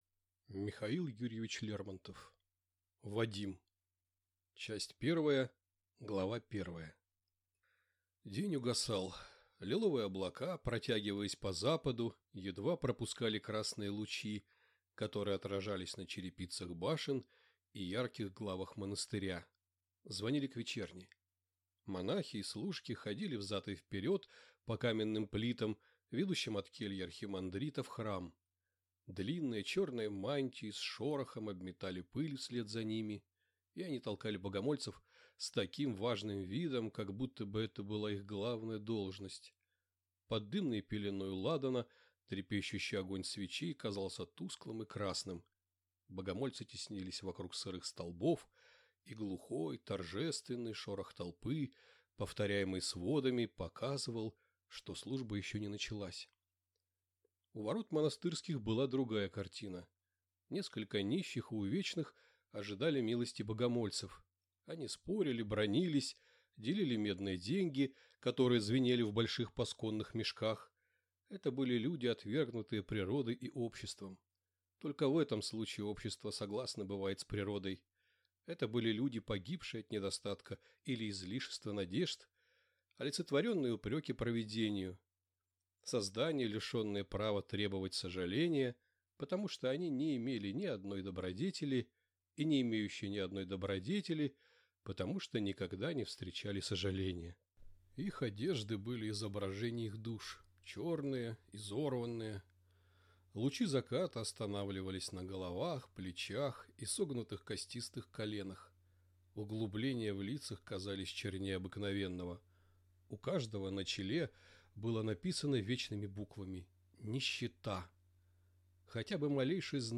Аудиокнига Вадим - купить, скачать и слушать онлайн | КнигоПоиск